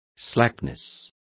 Complete with pronunciation of the translation of slackness.